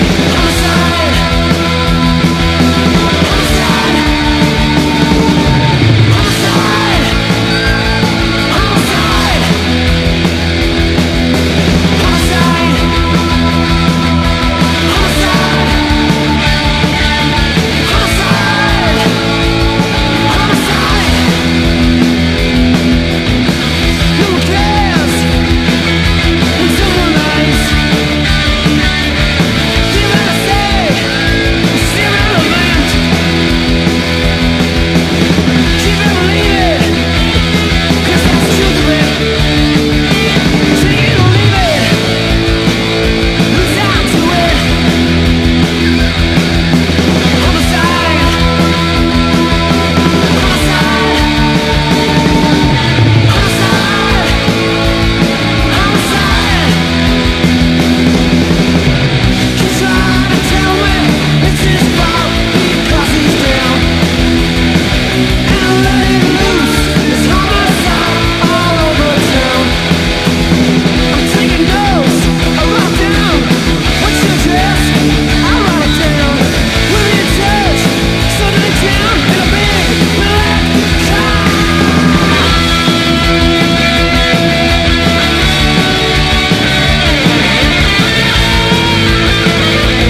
ROCK / PUNK / 80'S～ / Oi! / STREET PUNK (GER)
どれも前のめりな勢いとキャッチー＆パンキッシュなメロディーが気持ちよく弾けていて、即シンガロングOKな仕上がり！